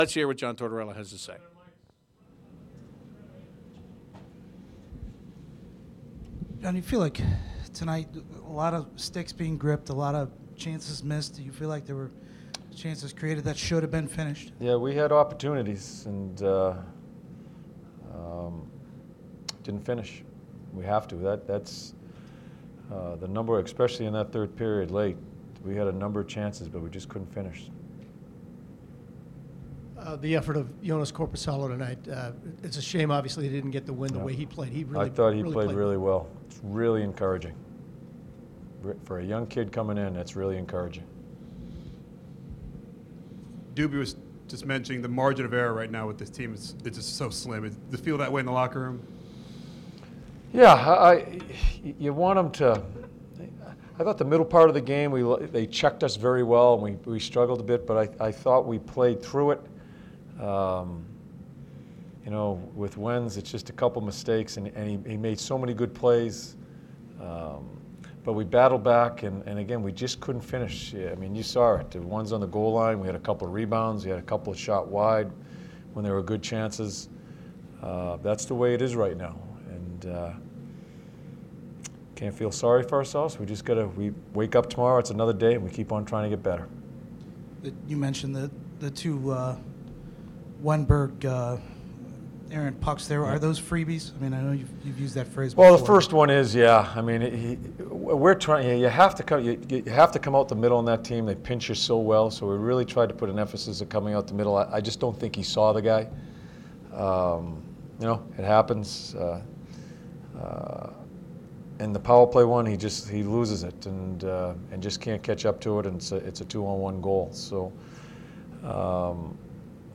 Head Coach John Tortorella addresses media after loss to Tampa Bay Lightning 2-1